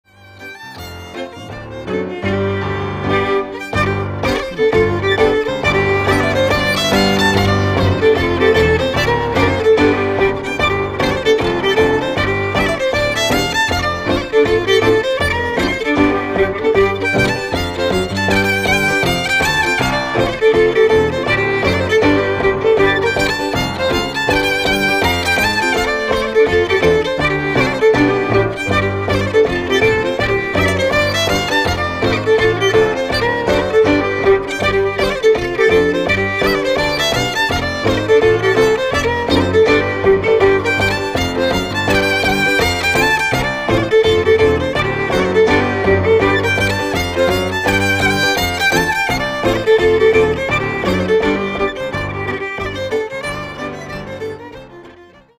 G minor tune